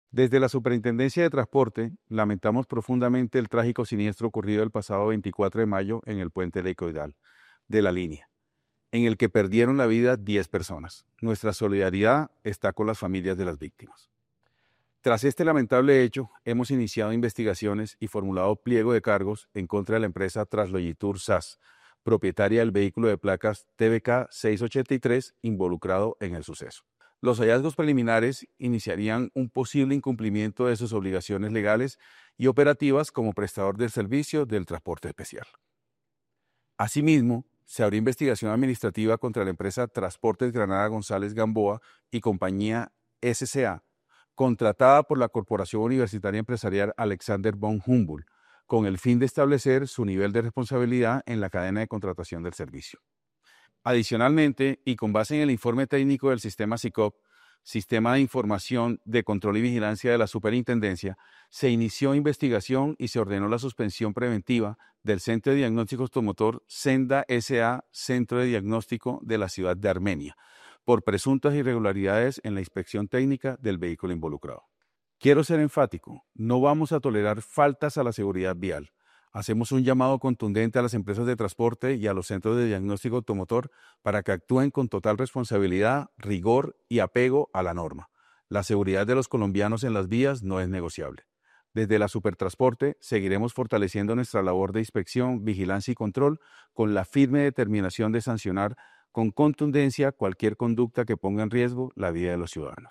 Superintendente de Transporte